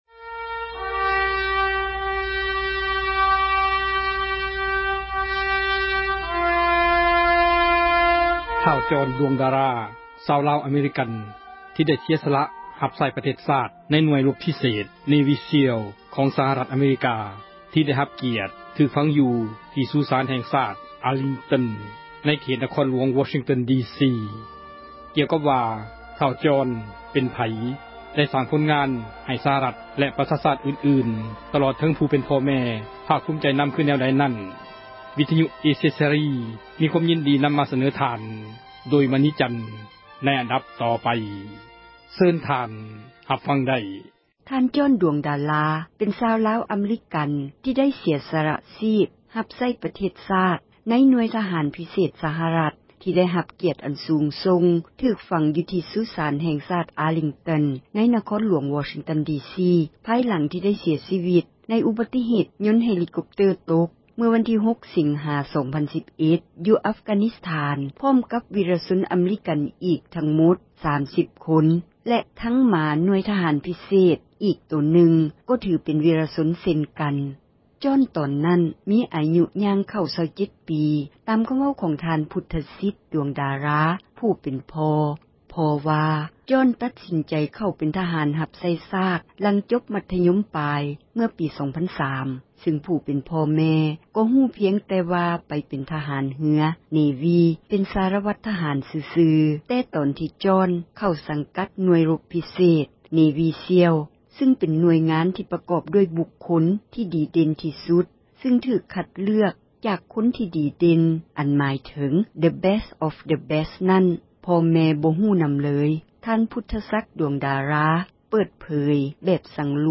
ສັມພາດຜູ້ປົກຄອງ